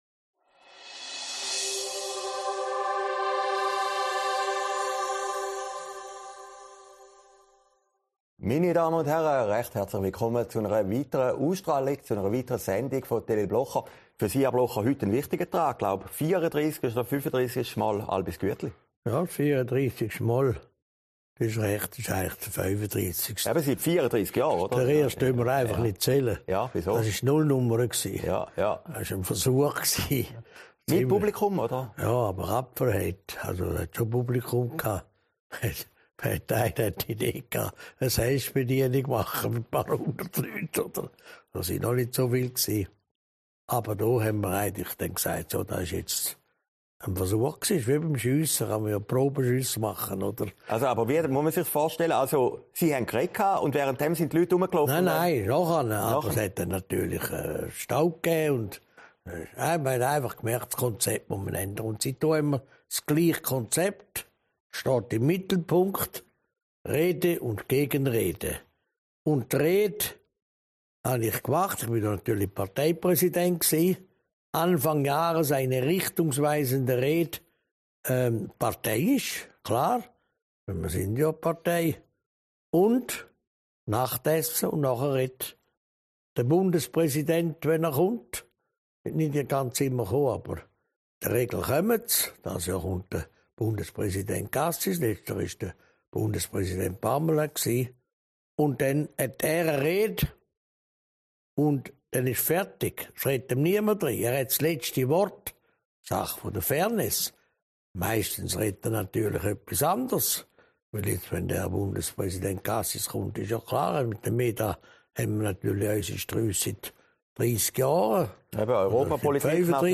Sendung vom 21. Januar 2022, aufgezeichnet in Herrliberg